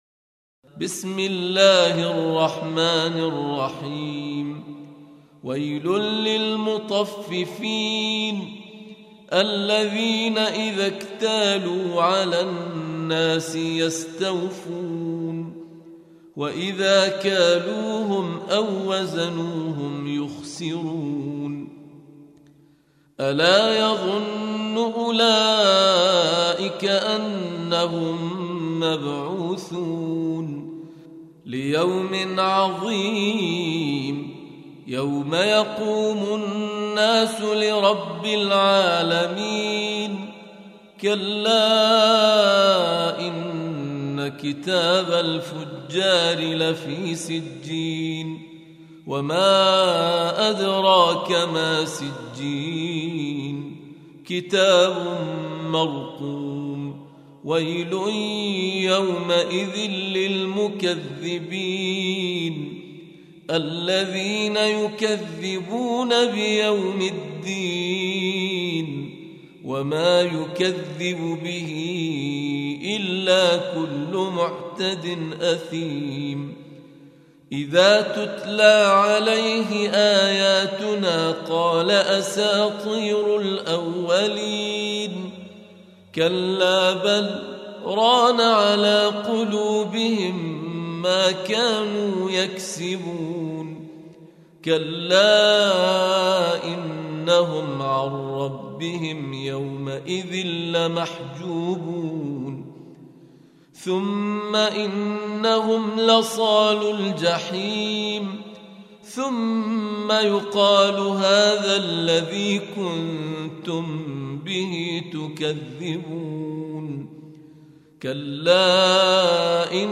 Reciting Murattalah Audio for 83. Surah Al-Mutaffifîn سورة المطفّفين N.B *Surah Includes Al-Basmalah